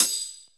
Boom-Bap Hat CL 65.wav